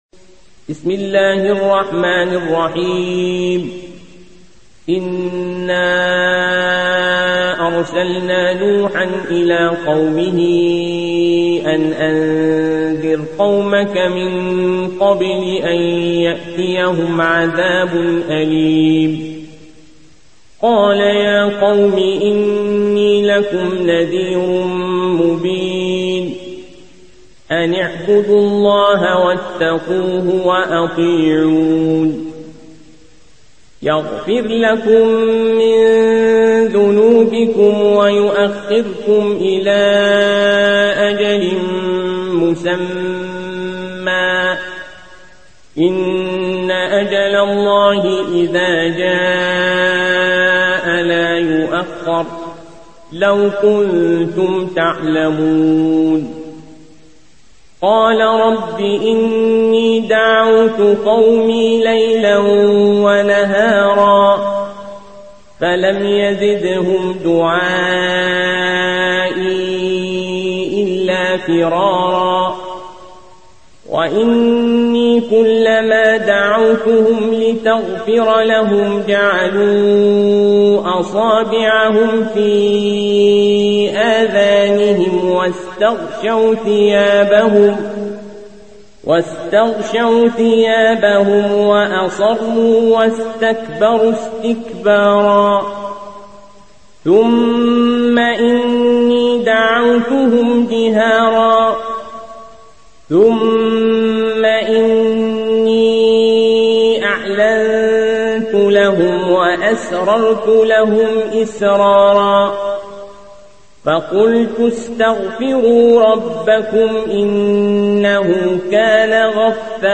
Hafs থেকে Asim